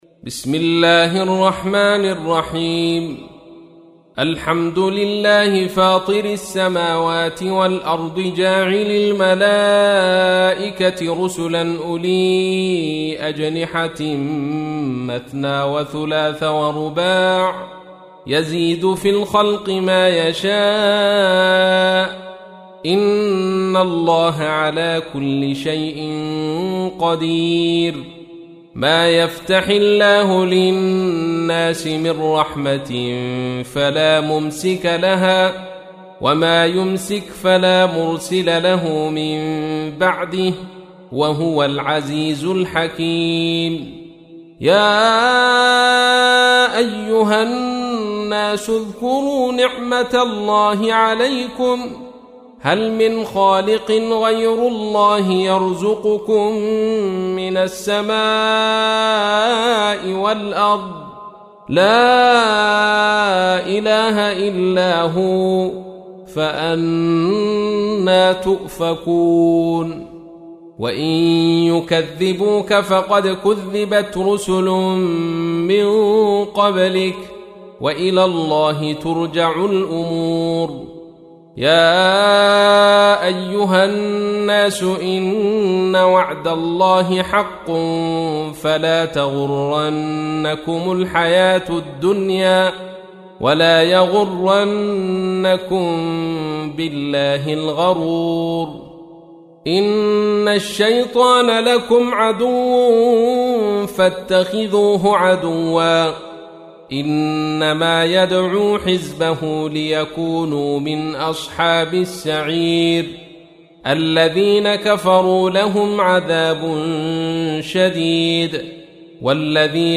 35. سورة فاطر / القارئ